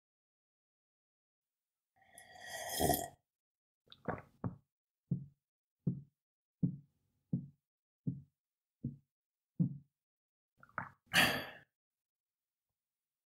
دانلود صدای خوردن آب از ساعد نیوز با لینک مستقیم و کیفیت بالا
جلوه های صوتی